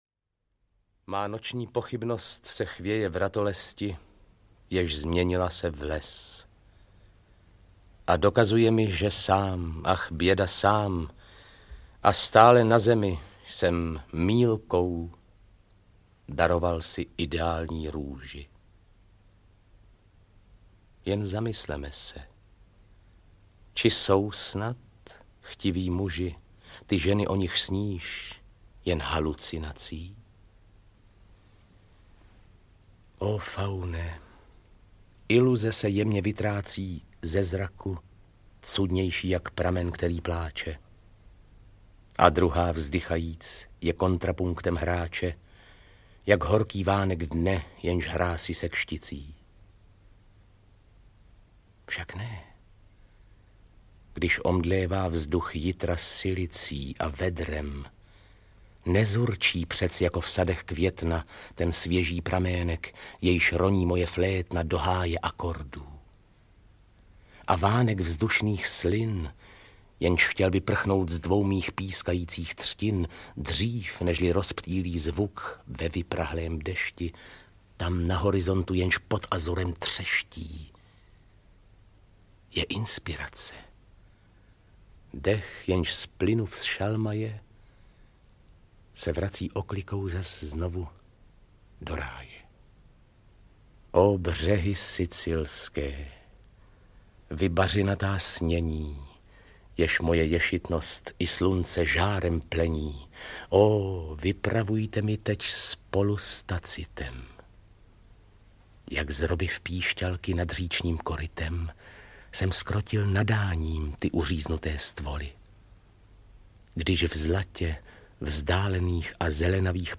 Audiokniha Francouzská poesie z dob impresionismu obsahuje nahrávky básní autorů jako Charles Baudelaire, Jean-Arthur Rimbaud, Paul Verlaine a dalších v podání předních českých herců.
Ukázka z knihy
• InterpretVáclav Voska, Luděk Munzar, Josef Chvalina, Otomar Krejča